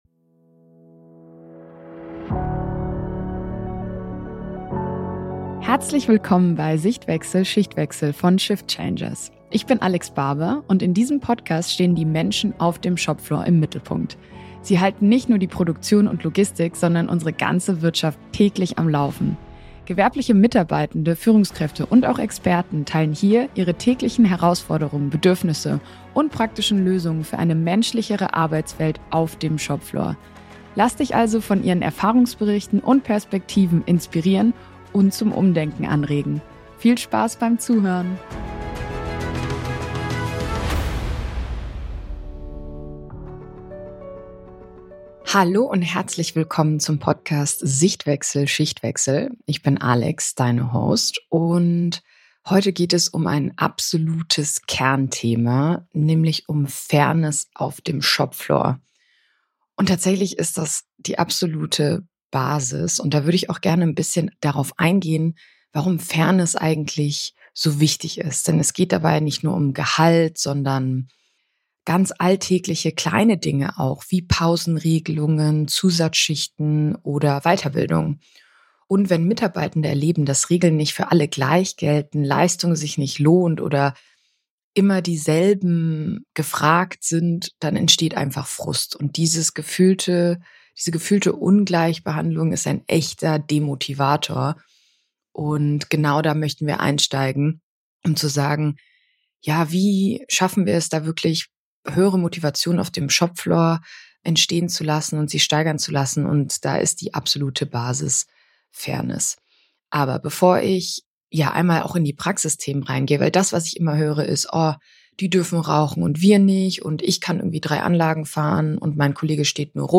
In dieser Solo-Folge spreche ich über ein Thema, das in kaum einer Zufriedenheitsumfrage fehlt und trotzdem oft unterschätzt wird: Fairness im Arbeitsalltag. Denn Fairness ist kein „weiches Thema“, sondern die Basis für psychologische Sicherheit, Vertrauen und Zusammenarbeit, gerade dort, wo es eng getaktet zugeht.